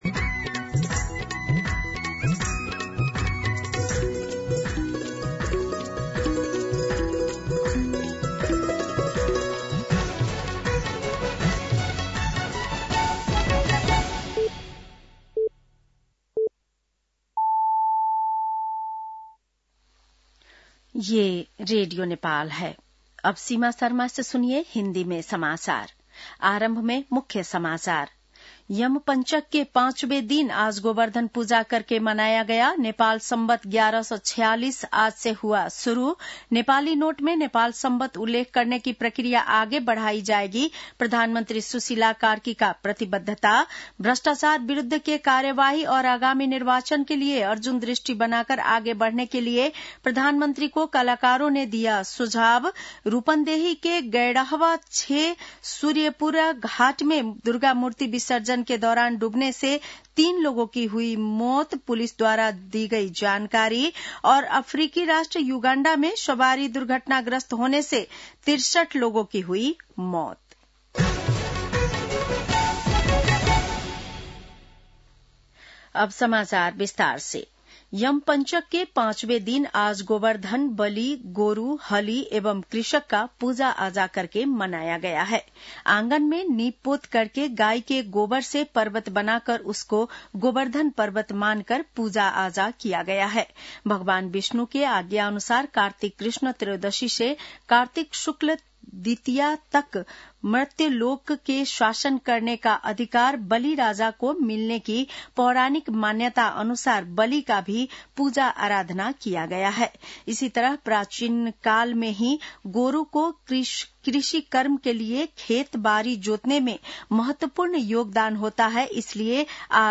बेलुकी १० बजेको हिन्दी समाचार : ५ कार्तिक , २०८२